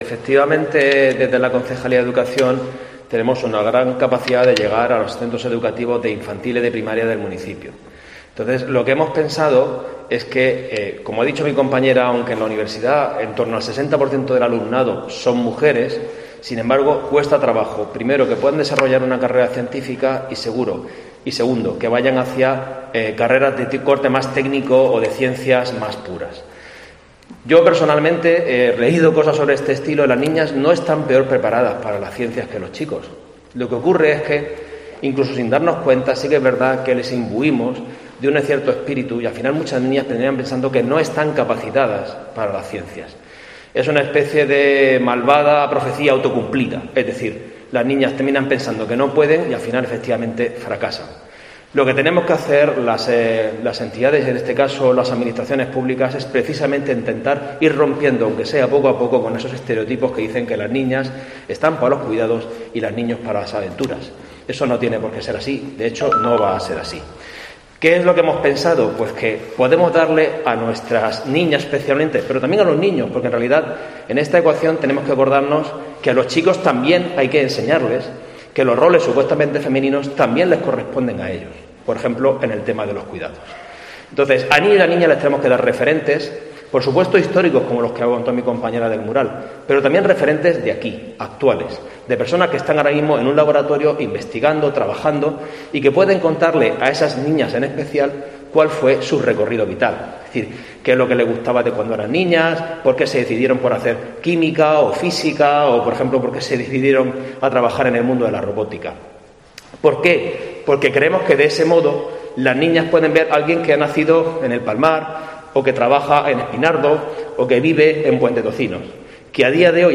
Antonio Benito, concejal de Educación del Ayuntamiento de Murcia